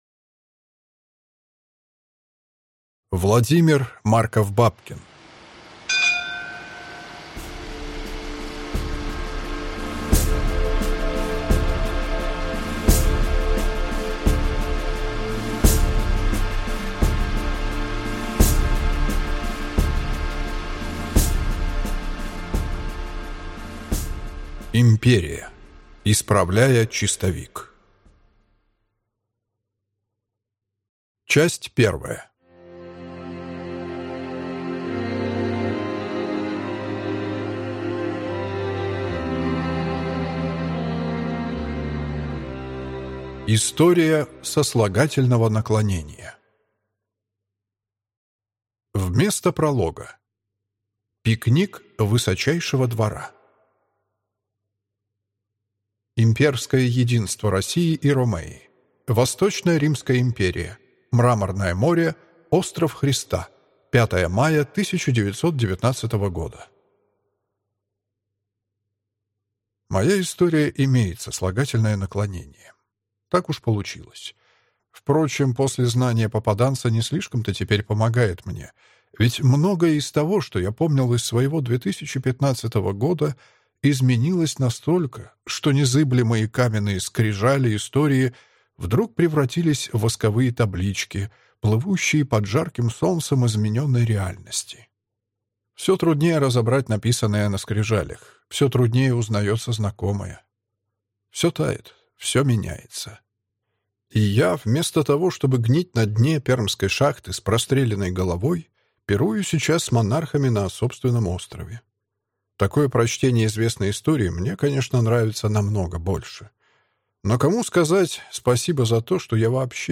Аудиокнига Империя. Исправляя чистовик | Библиотека аудиокниг